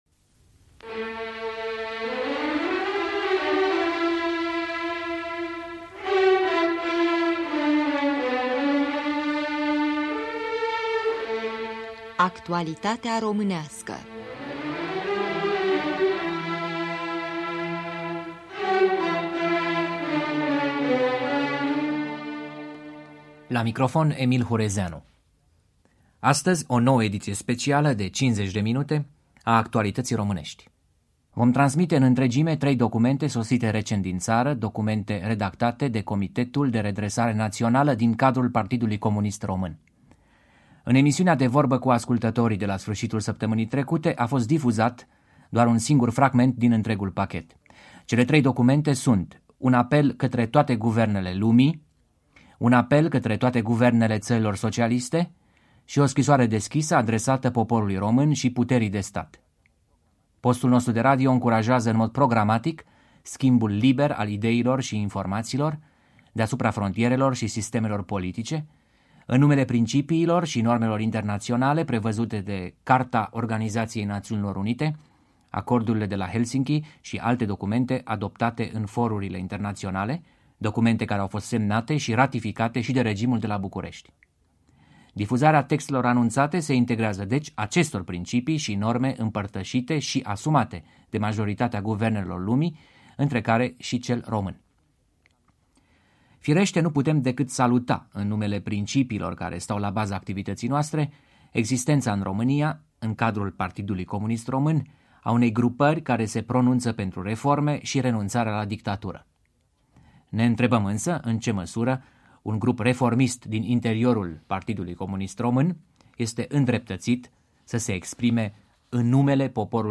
La București era în plină desfășurare al 14-lea Congres PCR. Emil Hurezeanu prezintă trei documente sosite din România înainte de începerea Congresului, semnate de Comitetul de redresare națională din cadrul Partidului Comunist Român: un apel către toate guvernele lumii, un apel către toate guvernele țărilor socialiste și o scrisoare deschisă adresată poporului român și puterii de stat